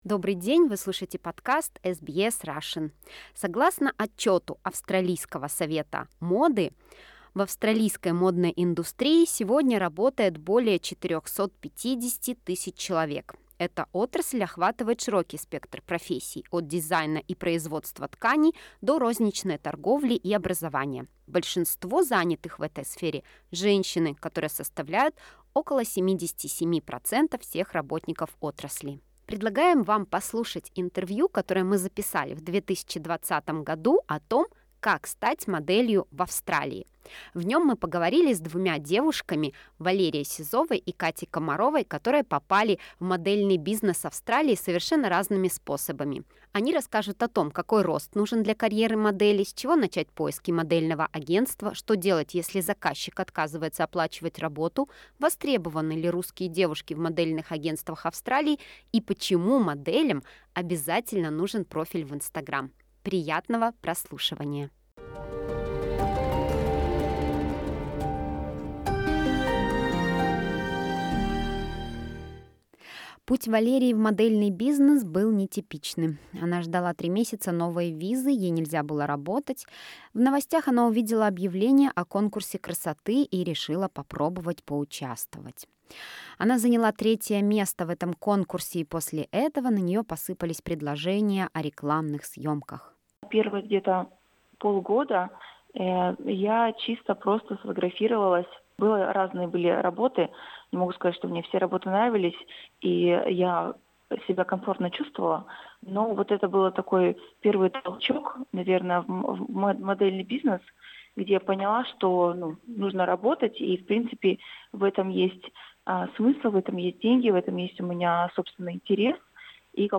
Это интервью было впервые опубликовано на сайте SBS Russian 20 сентября 2020 года. Какой рост нужен для карьеры модели?